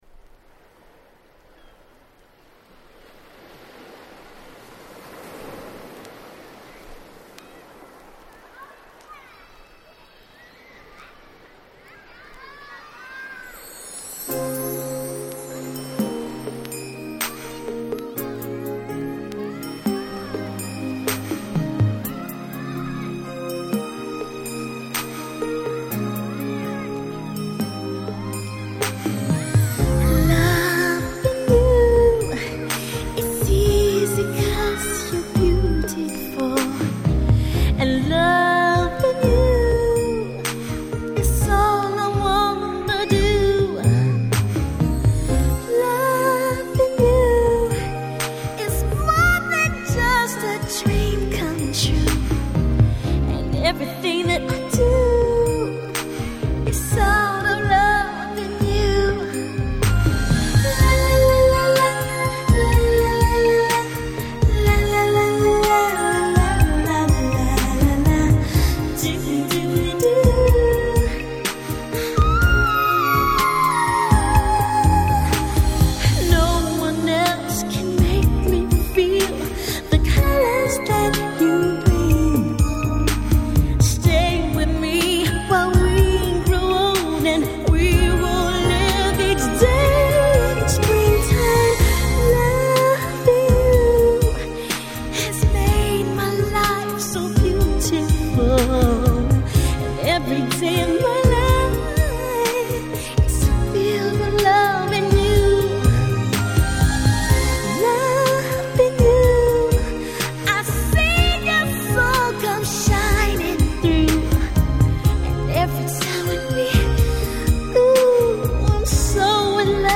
90's R&B Classic !!!
彼女のハイトーン・ヴォイスが輝く最高のカバーです！！